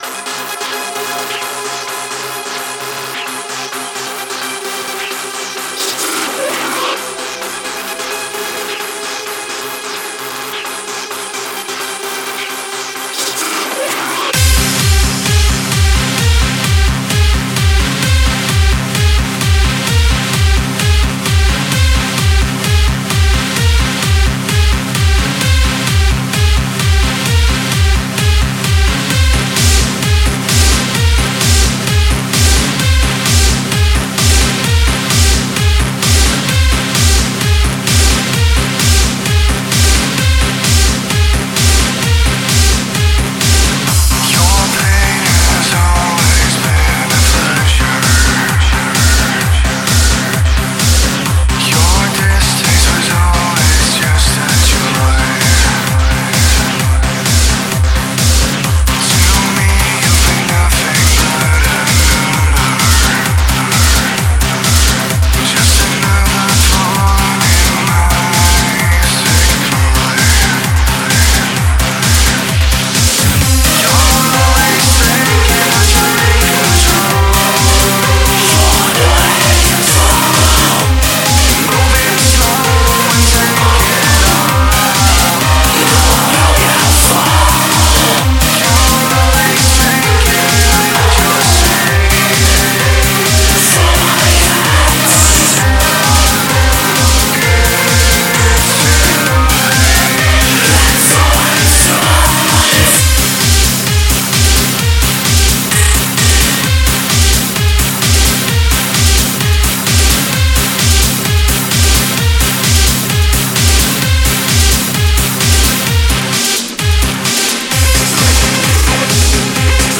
RSAudio RSA 01/18/2016 So now that we’ve played more than a little bit of Synthpop, its time to bring some balance to the force and play a little bit from the Dark Side. This week we delve into the ever so slightly heavier side of EBM.